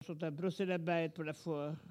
Collectif atelier de patois